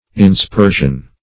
Search Result for " inspersion" : The Collaborative International Dictionary of English v.0.48: Inspersion \In*sper"sion\, n. [L. inspersio.] The act of sprinkling.